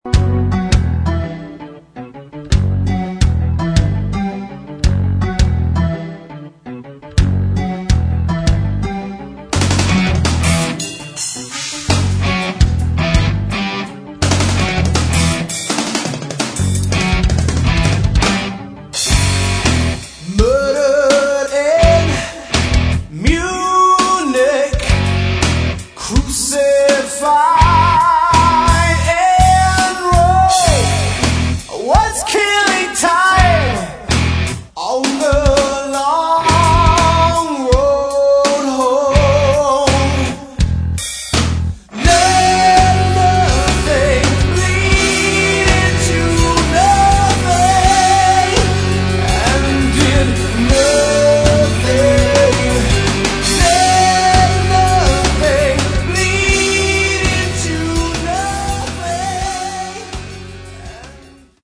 Metal
клавиши, фортепьяно